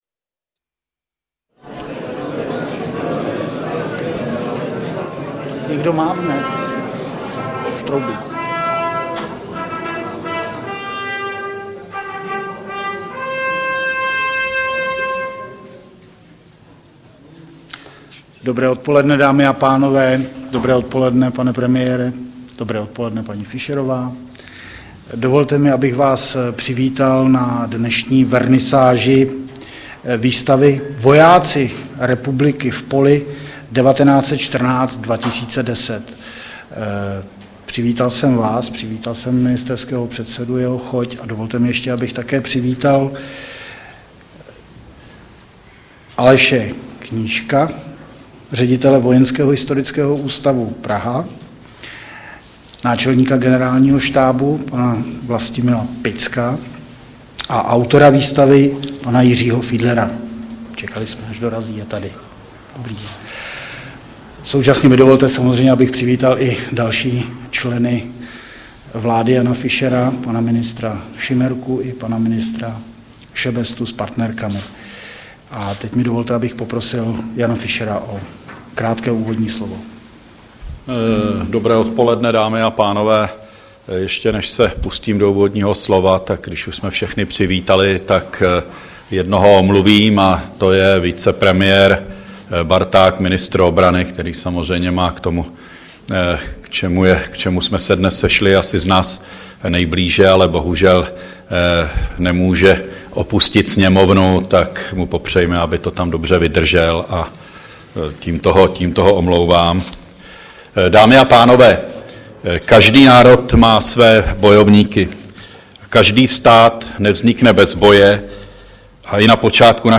Předseda vlády Jan Fischer ve středu 3. února projevem zahájil výstavu